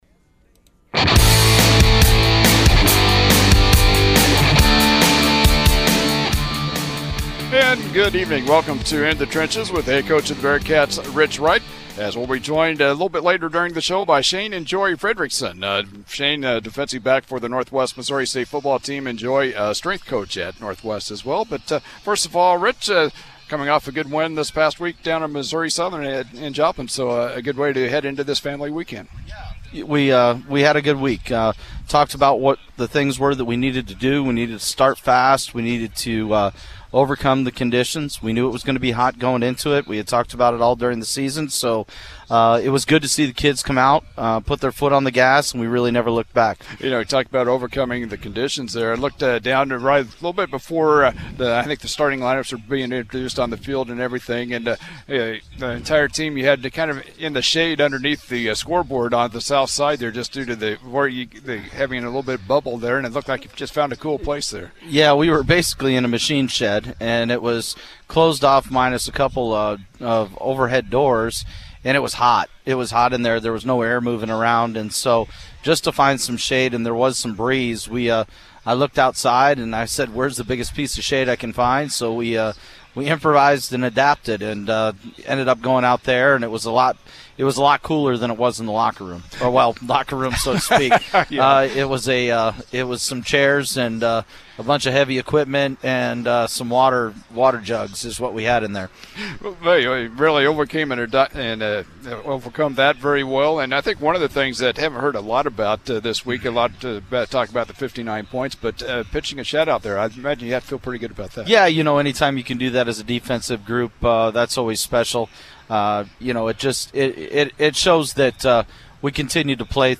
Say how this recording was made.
Catch In the Trenches every Thursday at 6:00pm live from A&G Restaurant in downtown Maryville.